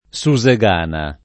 [ S u @ e g# na ]